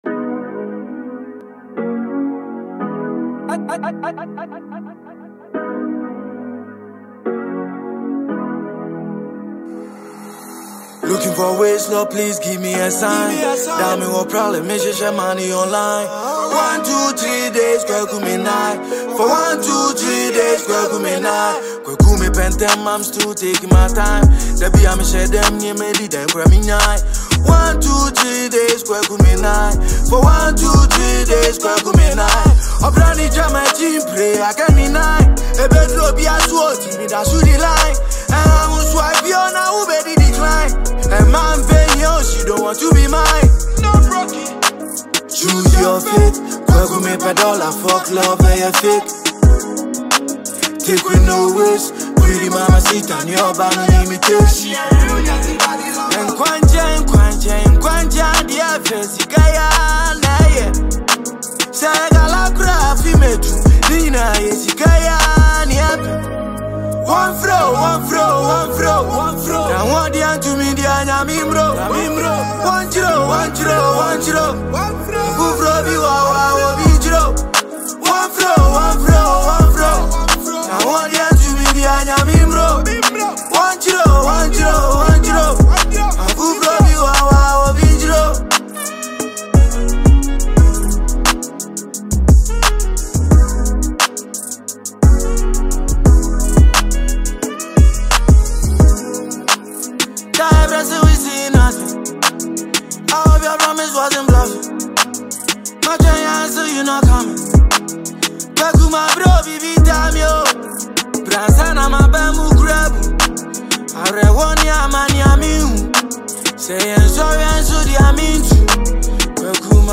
Ghanaian award-winning rapper